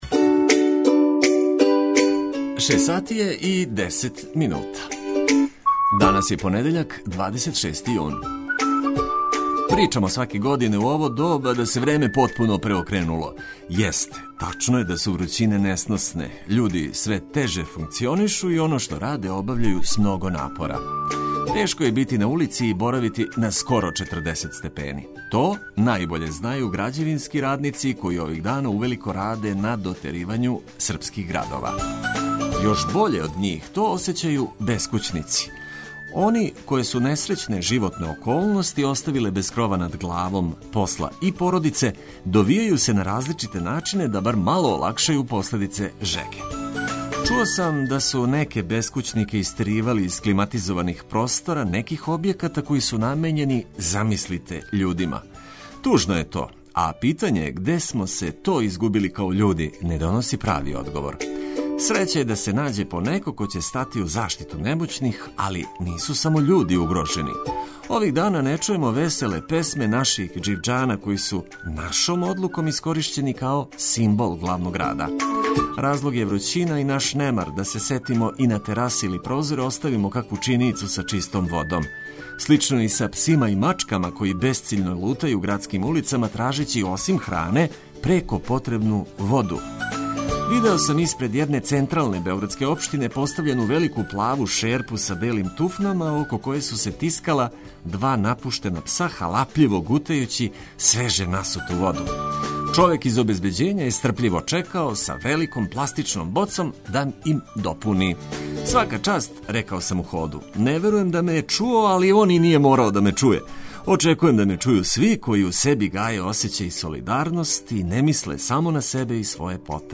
Припремили смо информације које ће вас сигурно занимати, а добра музика и овог јутра биће наше главно обележје.